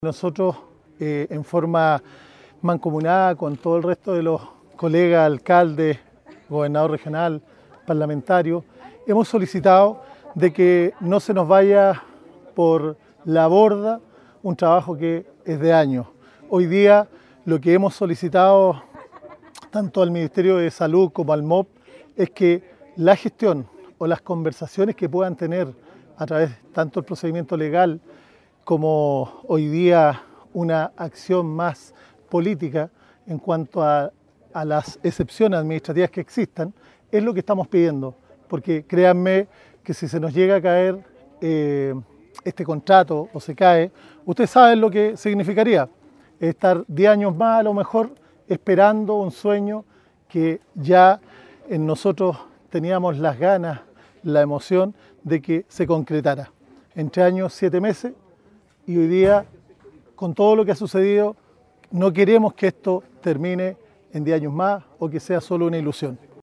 Asimismo, Cristian Oses, alcalde de Santa Bárbara, advirtió que “si se cae este contrato, lo que significa es estar diez años más esperando que se concrete este sueño”.